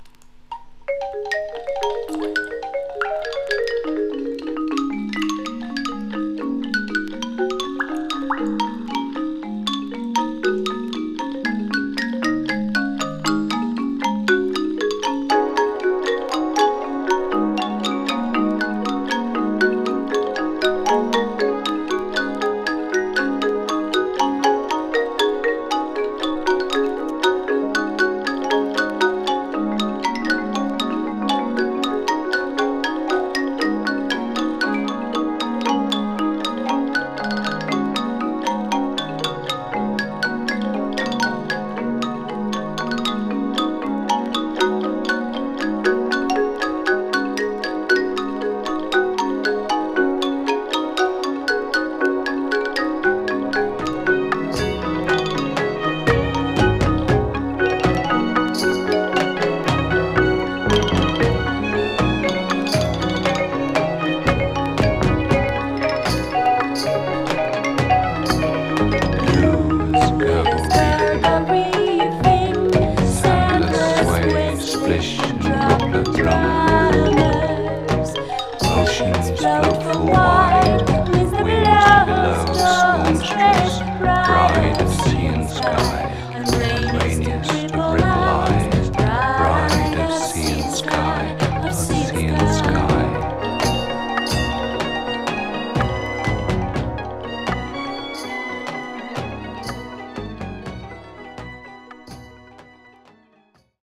> ELECTRO/NEW WAVE